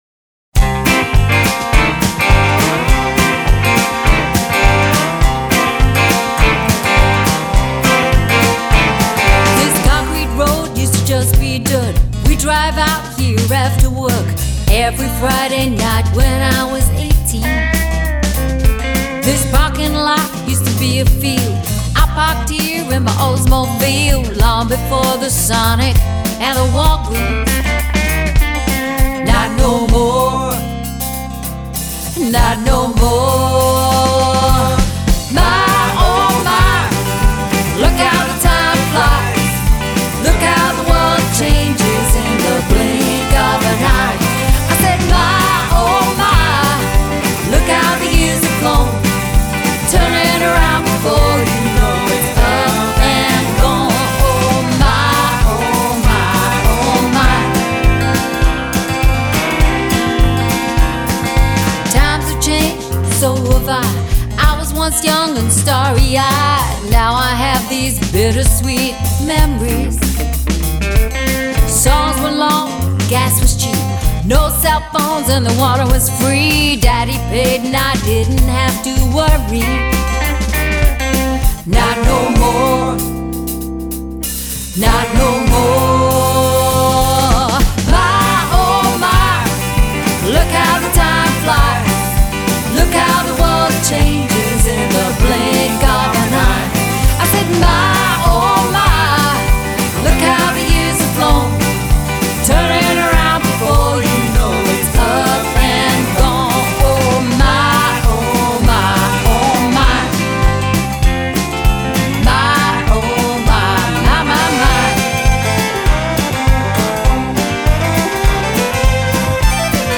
is a radio-friendly re-mastering
rich harmonies and edgy guitars